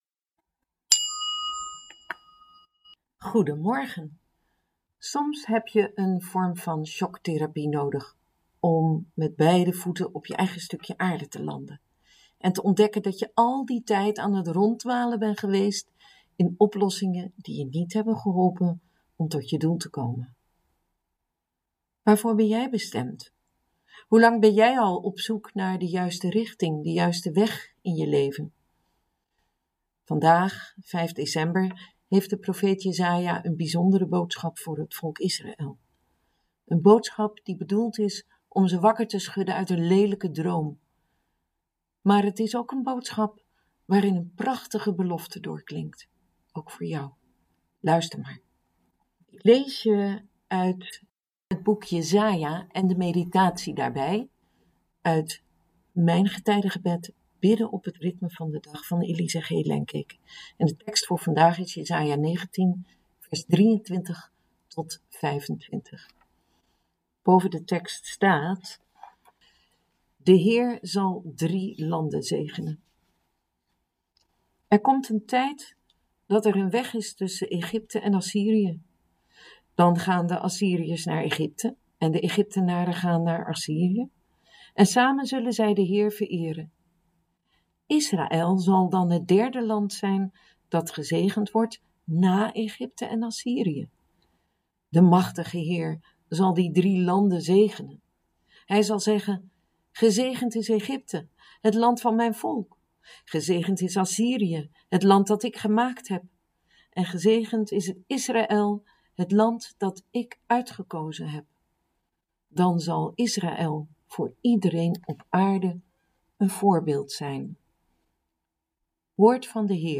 Ik lees je vandaag de dagboektekst en meditatie uit Mijn getijdengebed – bidden op het ritme van de dag van Elise G. Lengkeek: Jesaja 19:23-25